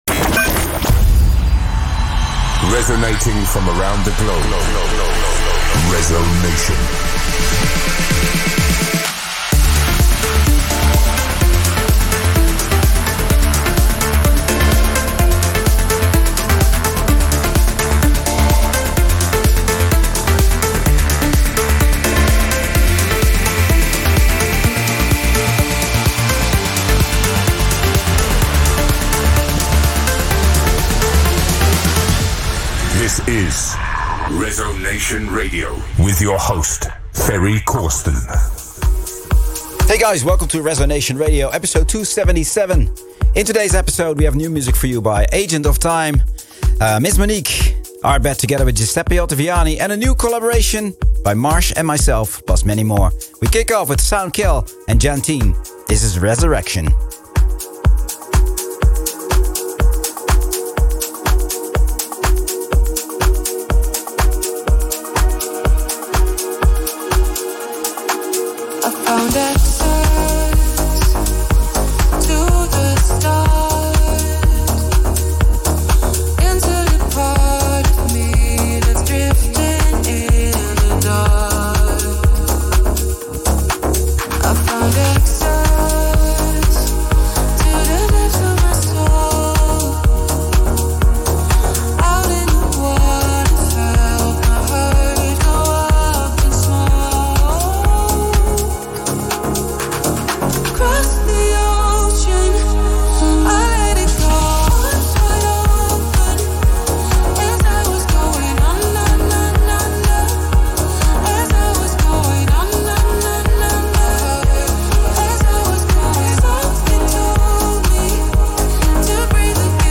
melodic trance and progressive sounds